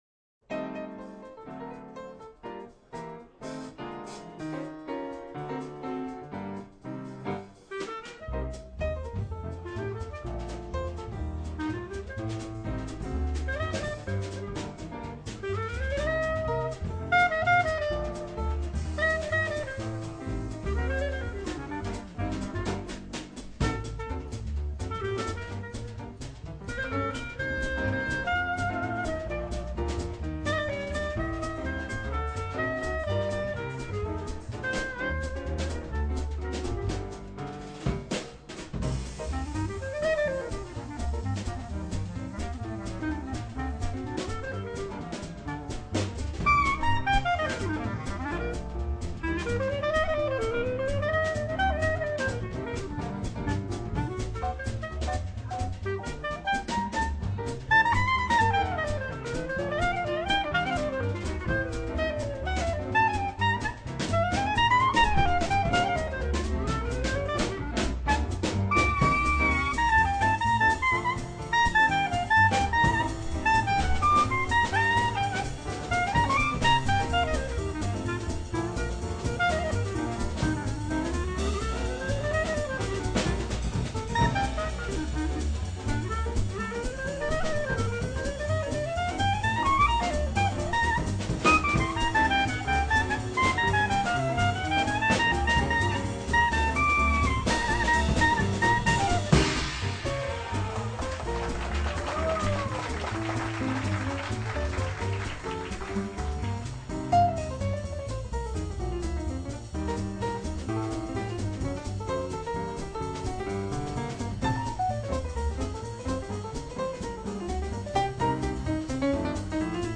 Диксиленд
swing